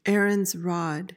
PRONUNCIATION:
(air-uhnz ROD)